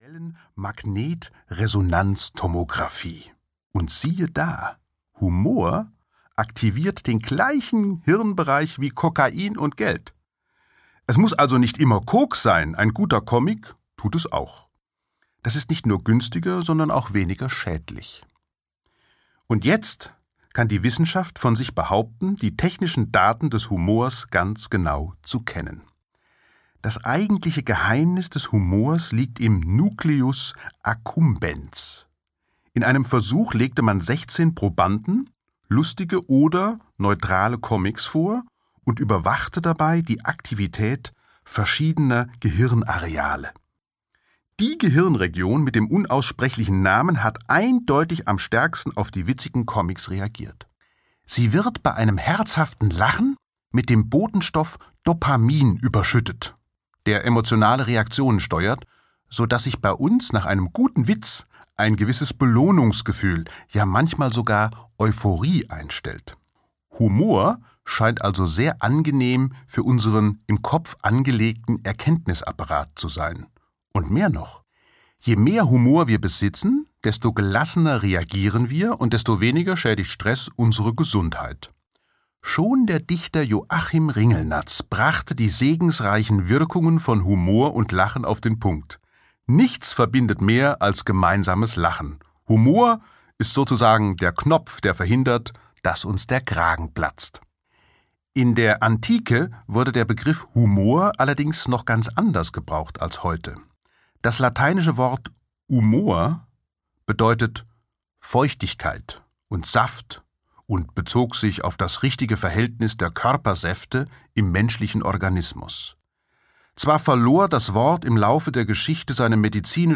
Der Außerirdische ist auch nur ein Mensch - Harald Lesch - Hörbuch